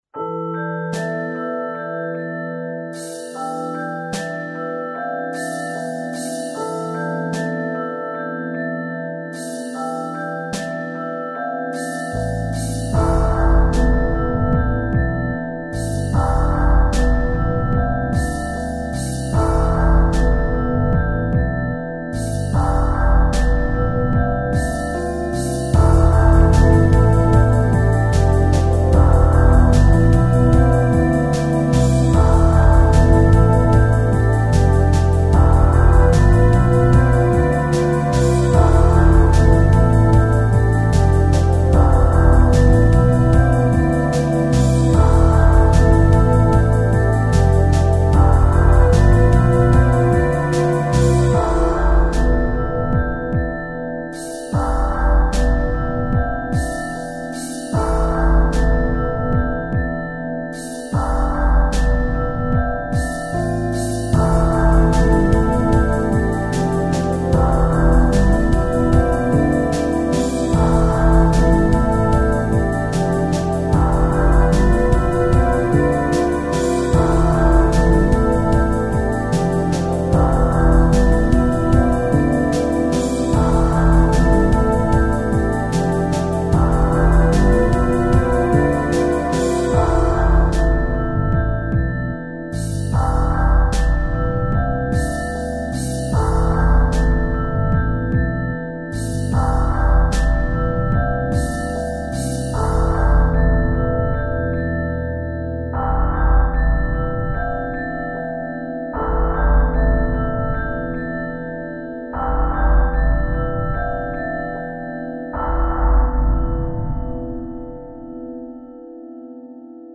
Contemplative Ambo Rock